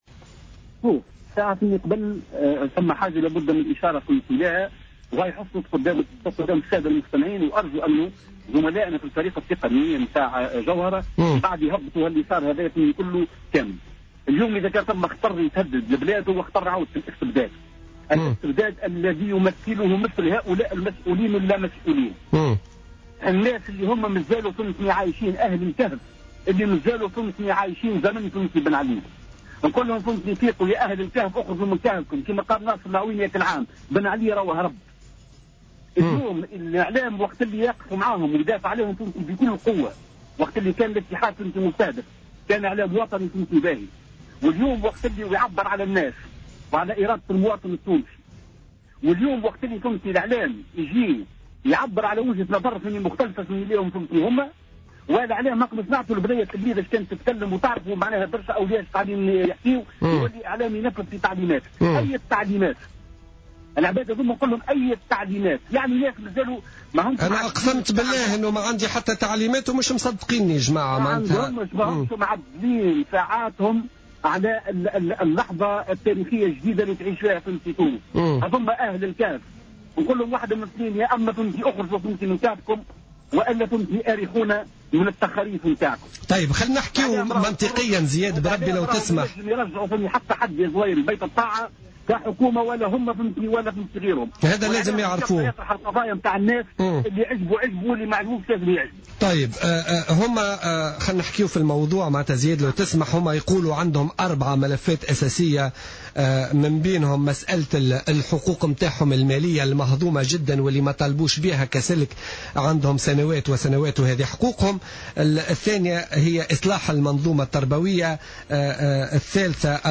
Dans son intervention sur les ondes de Jawhara FM ce lundi 2 mars 2015